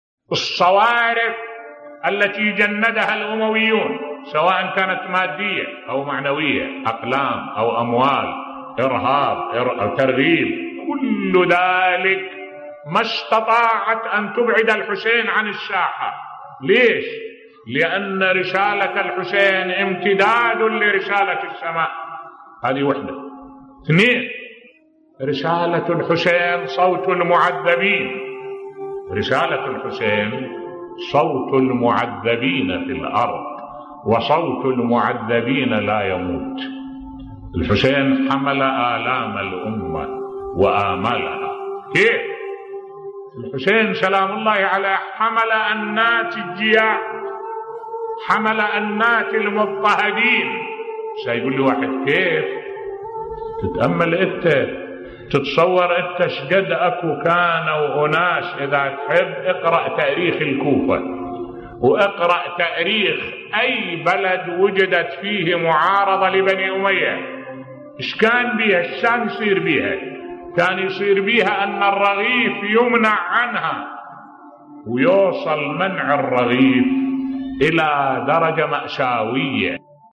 ملف صوتی فشل الأمويين في إبعاد الحسين عن الساحة الاسلامية بصوت الشيخ الدكتور أحمد الوائلي